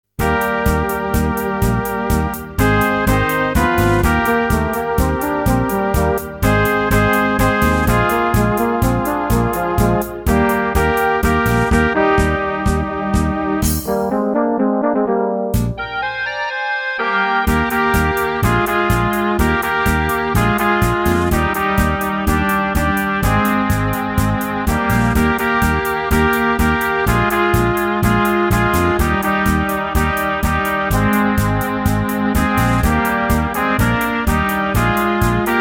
Rubrika: Národní, lidové, dechovka
Lidové tango